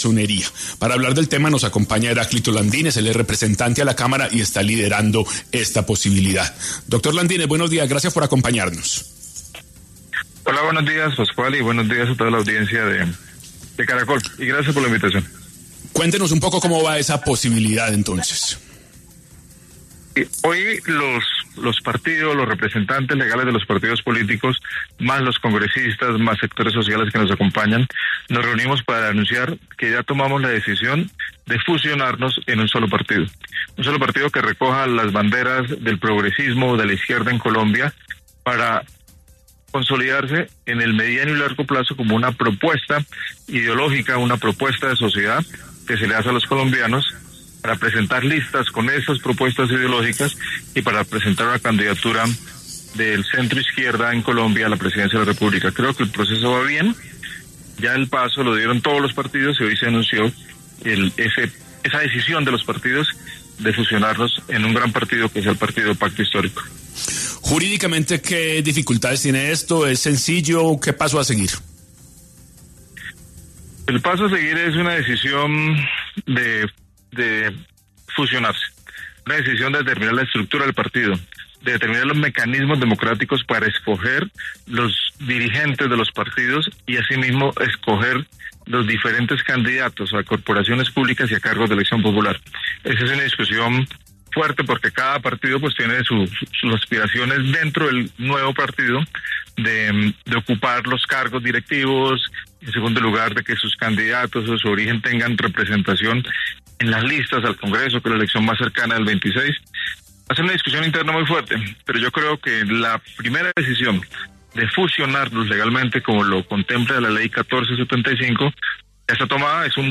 En 10AM de Caracol Radio, estuvo Heráclito Landinez, representante a la Cámara, quien habló sobre la alianza de cinco fuerzas políticas para la creación de un movimiento único de izquierda dentro del Pacto Histórico. Además, enfatizó que se realizará una consulta para elegir al candidato presidencial para las elecciones de 2026.